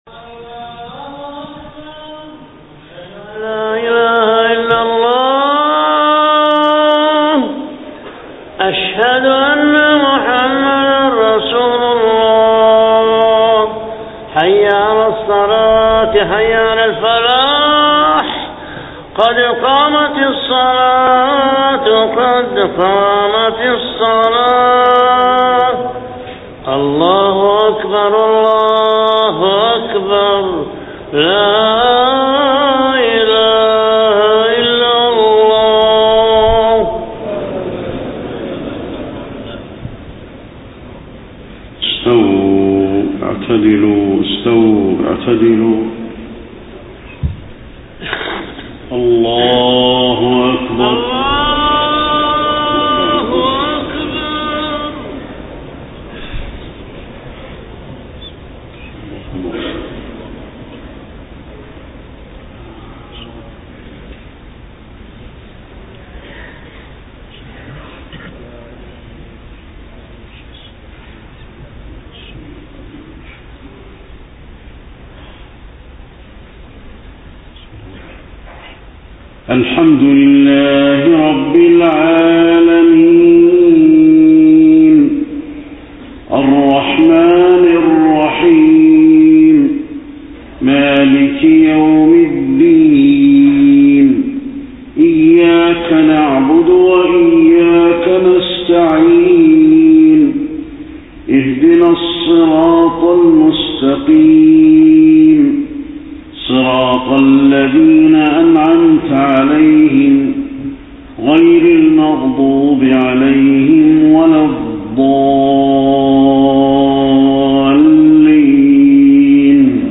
صلاة العشاء 9 ربيع الأول 1431هـ من سورة الإسراء 26-39 > 1431 🕌 > الفروض - تلاوات الحرمين